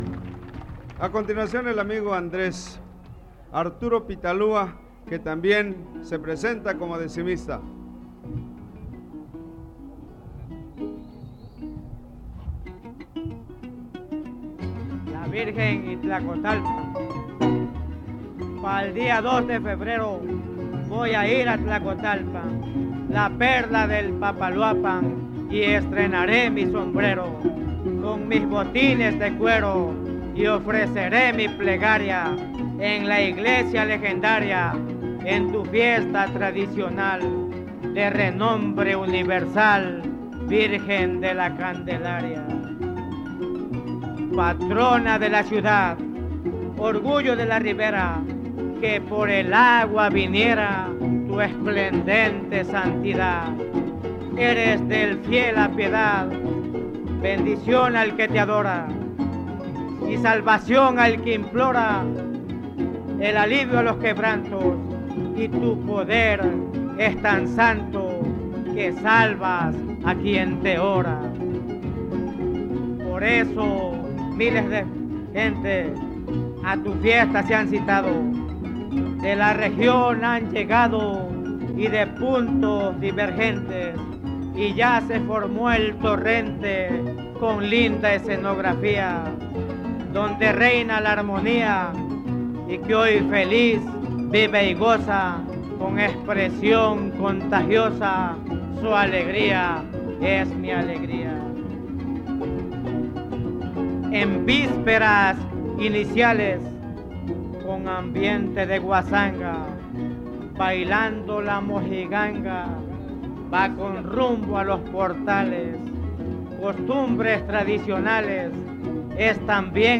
• Siquisirí (Grupo musical)
Noveno Encuentro de jaraneros